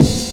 Kick (Classic).wav